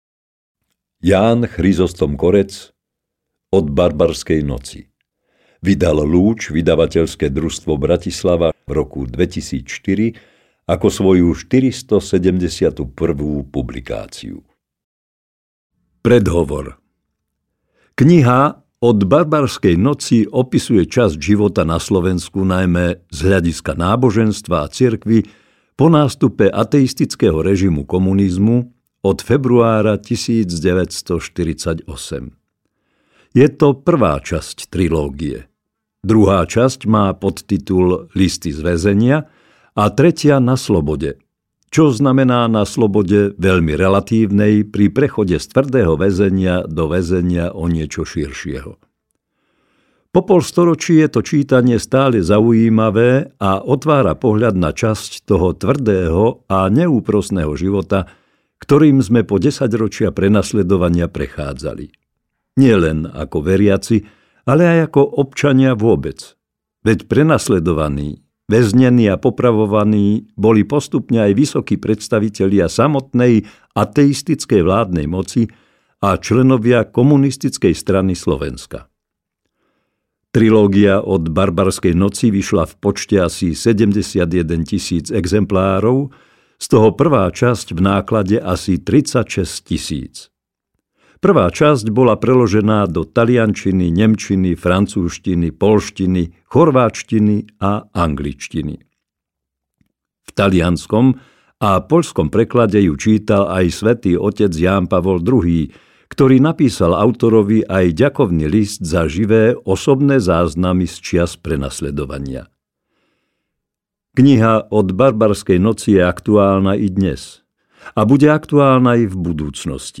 Od barbarskej noci audiokniha
Ukázka z knihy
• InterpretJán Chryzostom Korec